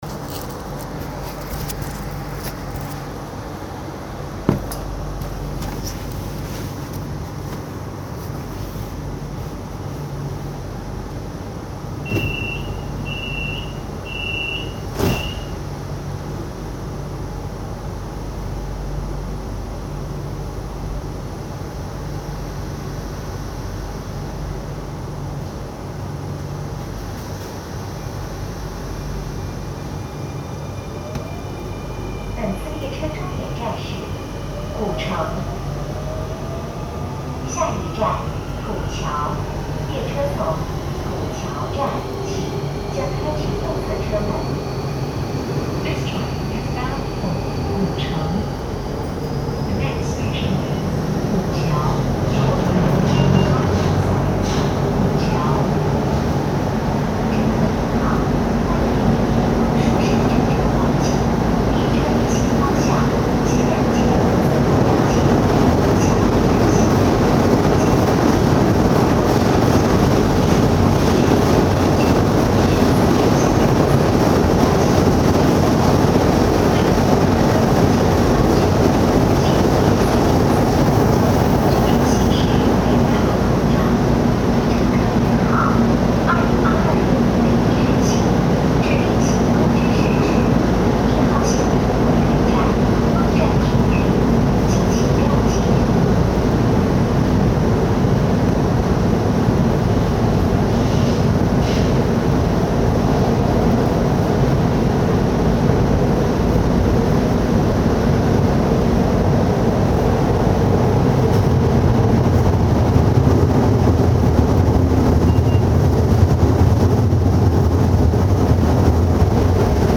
CRH1A-A-1186 - 走行音